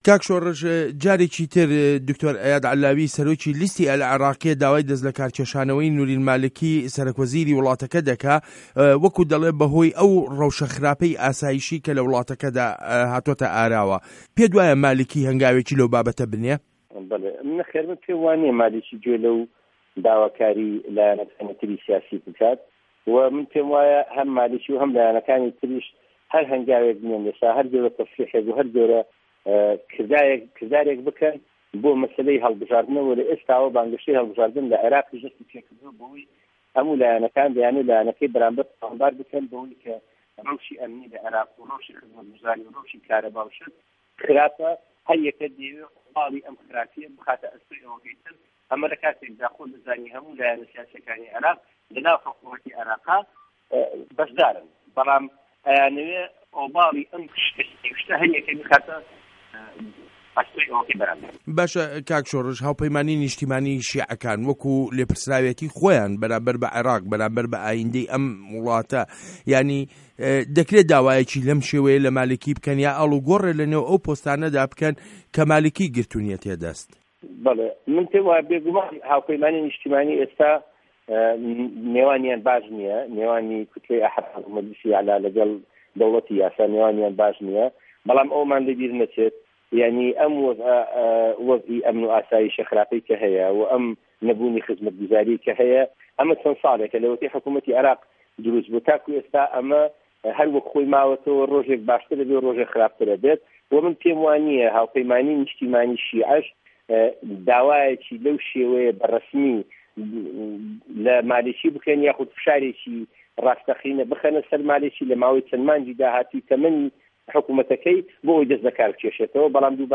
وتووێژ له‌گه‌ڵ شۆڕش حاجی